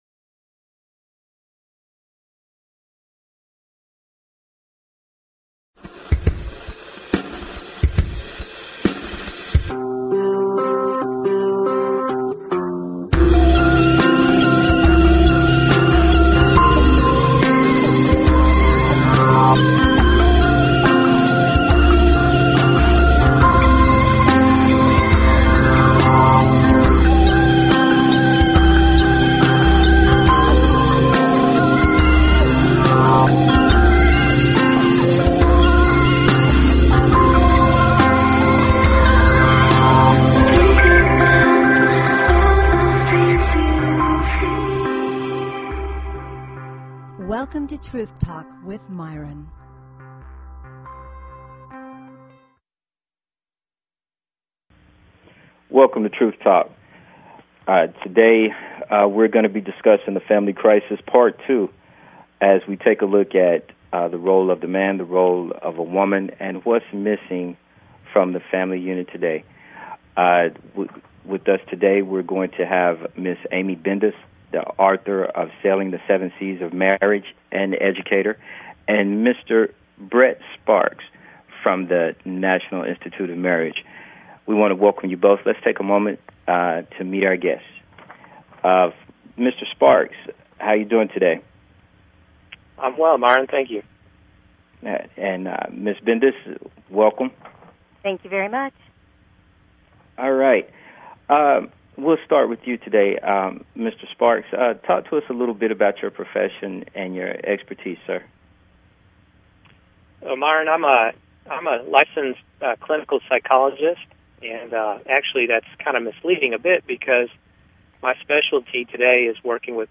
Talk Show Episode, Audio Podcast, Truth_Talk and Courtesy of BBS Radio on , show guests , about , categorized as